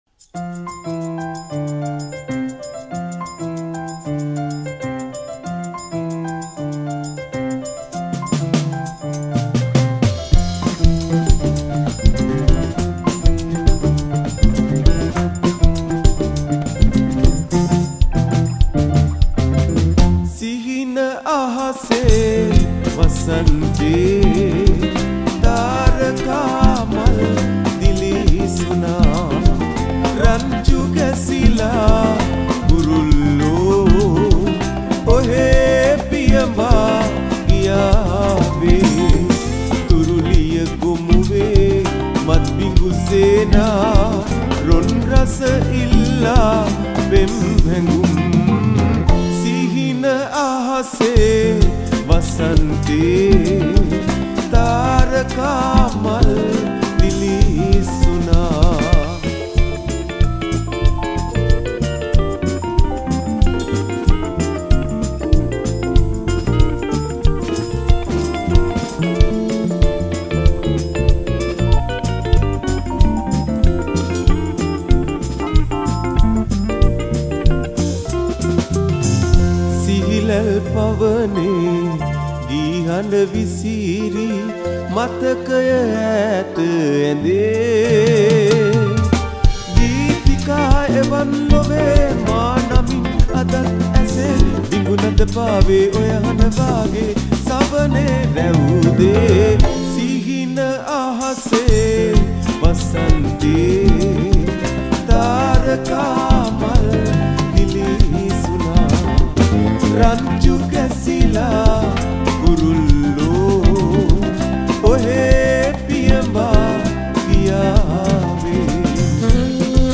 High quality Sri Lankan remix MP3 (6).
Covers